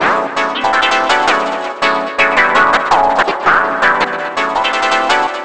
09 Elmofudd 165 Db.wav